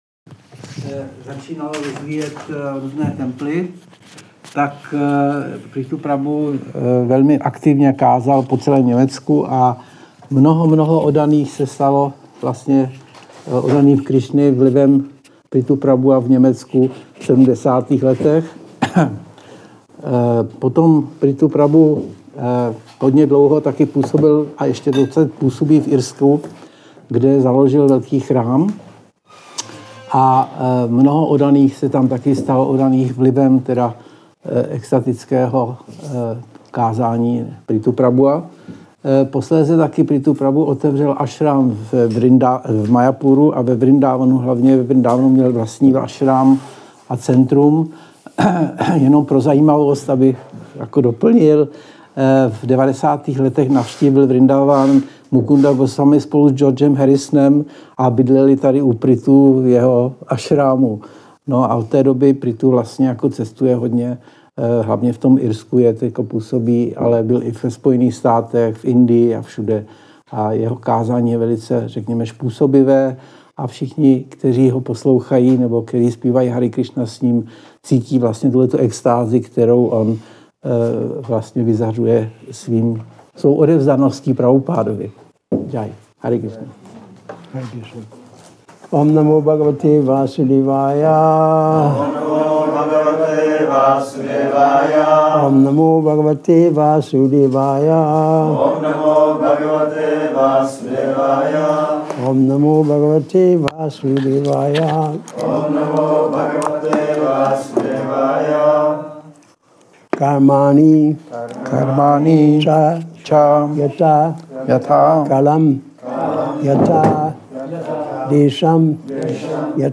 Přednáška SB-4.22.50
Šrí Šrí Nitái Navadvípačandra mandir